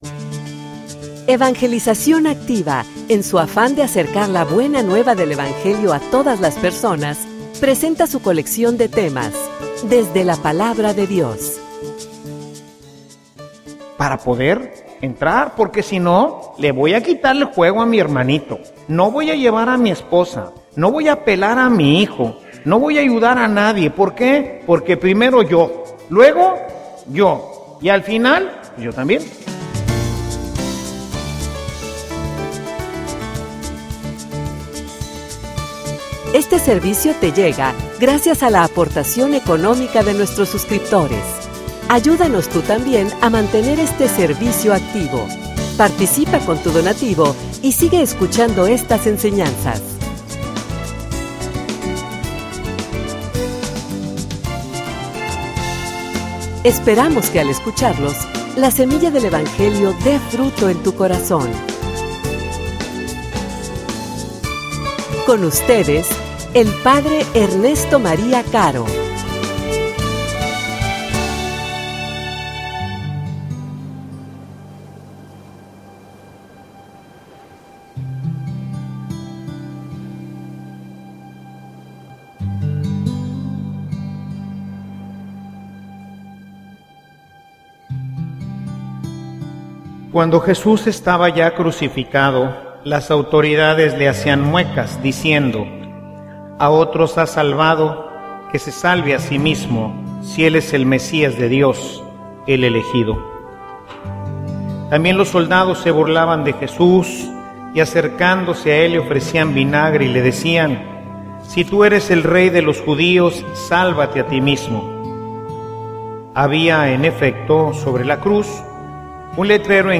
homilia_La_puerta_del_reino_es_la_cruz.mp3